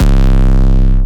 Up It 808.wav